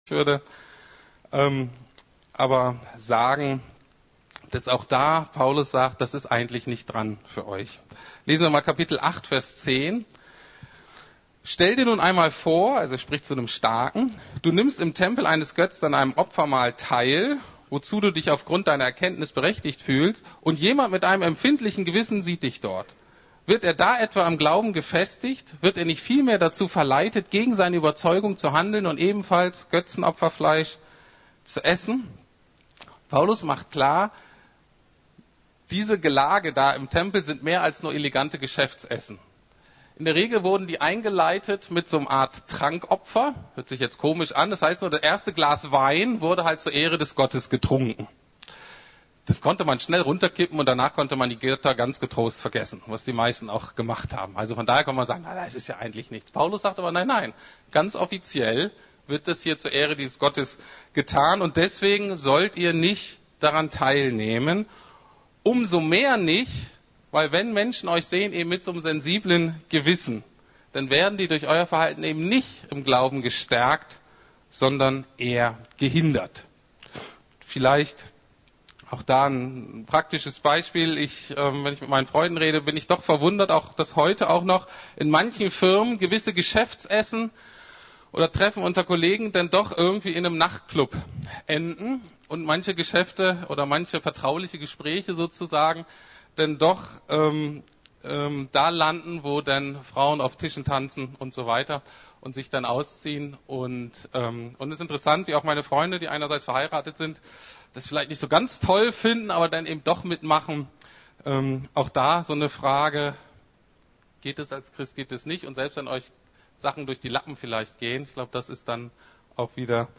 Es ist mir alles erlaubt!? - Kennzeichen echter Freiheit ~ Predigten der LUKAS GEMEINDE Podcast